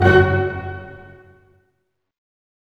Index of /90_sSampleCDs/Roland L-CD702/VOL-1/HIT_Dynamic Orch/HIT_Staccato Oct